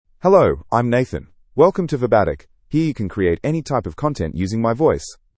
MaleEnglish (Australia)
Nathan is a male AI voice for English (Australia).
Voice sample
Male
Nathan delivers clear pronunciation with authentic Australia English intonation, making your content sound professionally produced.